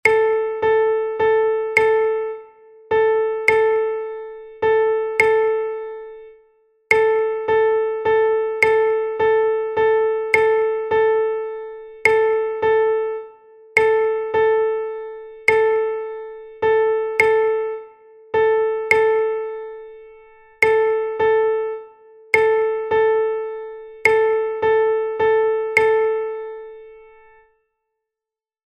Rhythmic dictation
dictado_ritmico_AUDIO.mp3